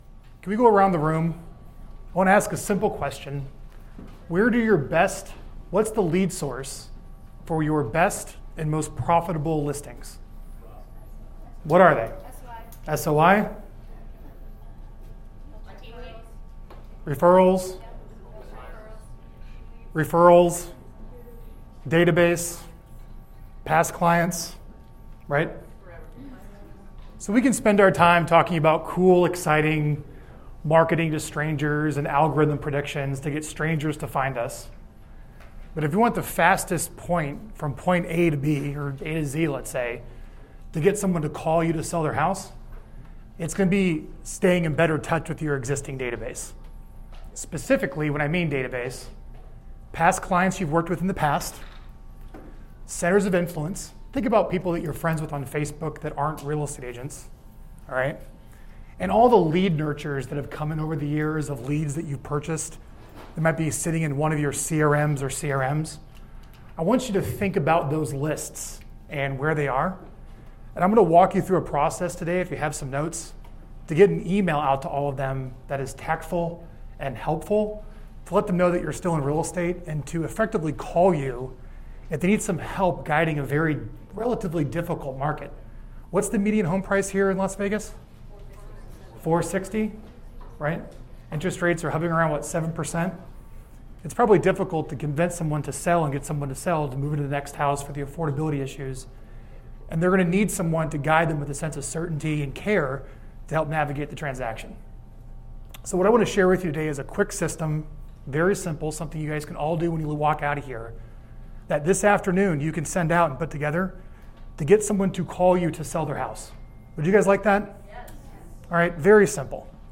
I was invited to speak at an event in Las Vegas during Inman back in July